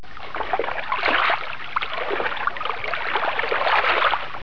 ocean.wav